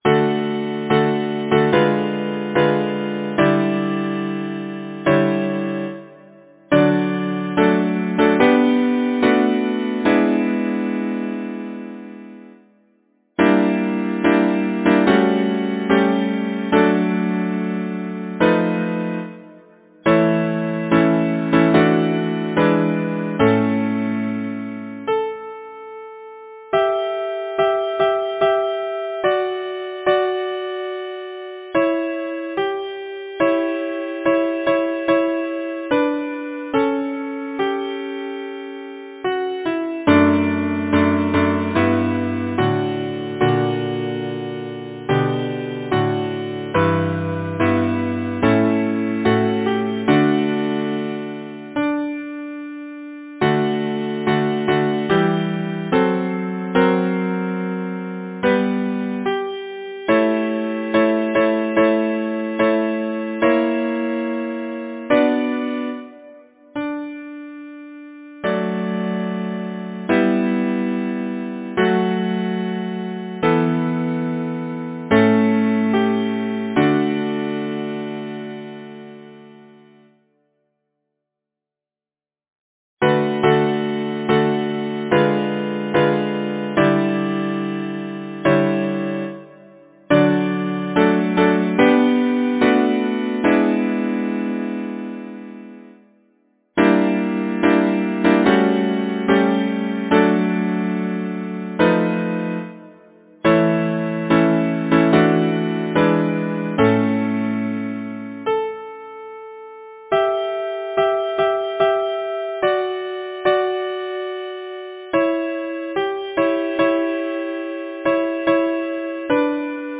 Title: Go, when the morning shineth Composer: Henry Lahee Lyricist: Jane Cross Simpson Number of voices: 4vv Voicing: SATB Genre: Secular, Partsong
Language: English Instruments: A cappella